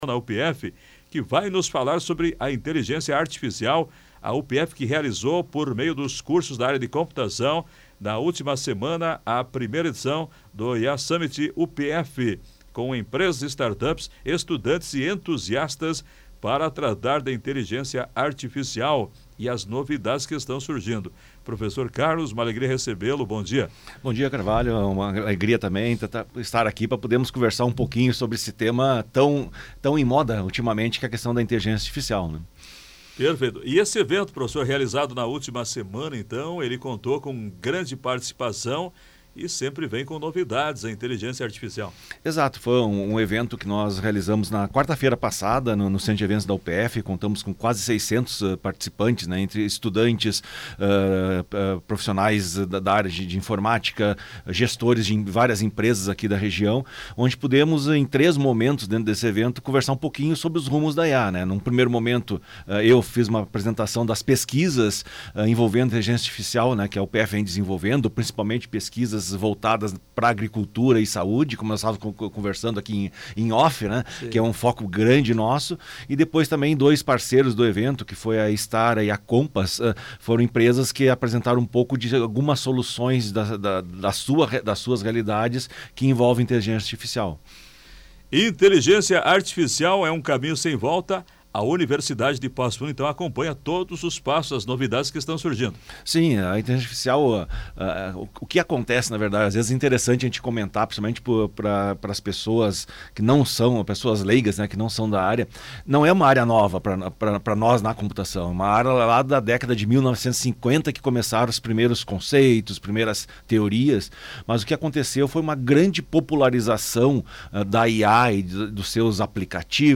Entrevista: professor da UPF apresenta as novidades que surgem com a Inteligência Artificial